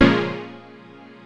HOUSE 9-R.wav